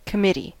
Ääntäminen
Vaihtoehtoiset kirjoitusmuodot cttee Ääntäminen US Tuntematon aksentti: IPA : /kə.ˈmɪ.ti/ IPA : /kɑ.mɪ.ˈtiː/ UK : IPA : /kɒ.mɪ.ˈtiː/ Lyhenteet ja supistumat (laki) Comm.